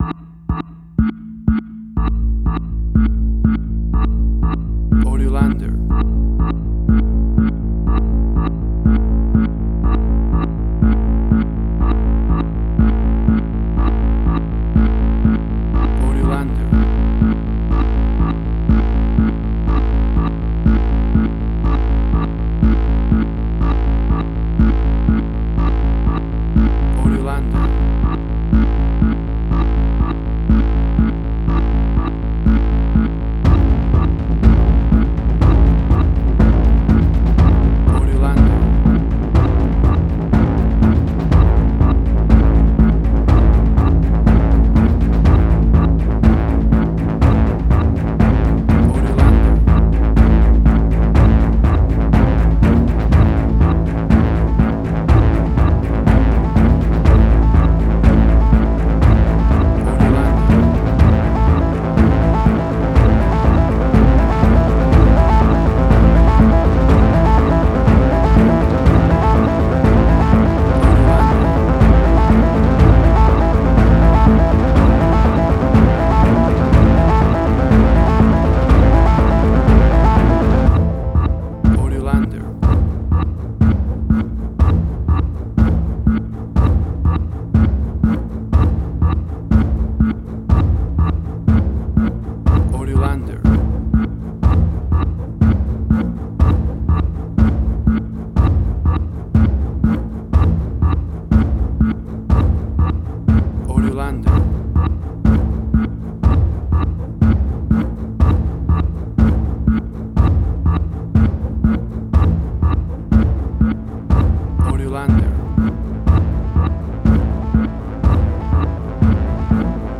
Modern Science Fiction Film, Similar Tron, Legacy Oblivion.
Tempo (BPM): 122